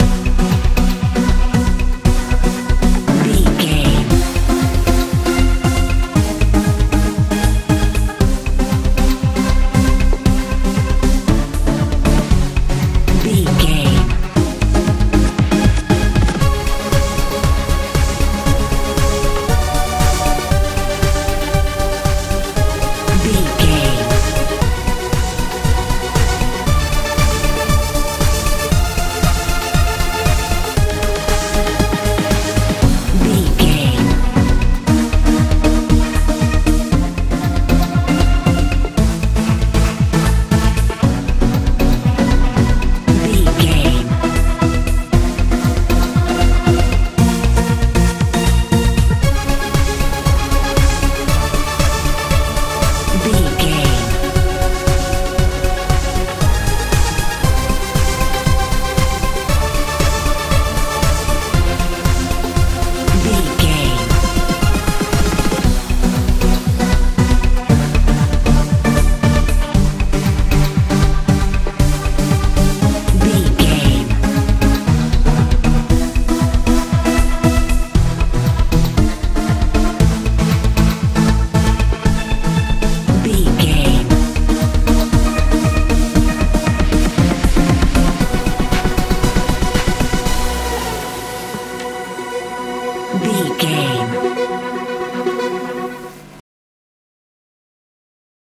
techno feel
Diminished
mystical
magical
synthesiser
bass guitar
drums
80s
90s
strange
futuristic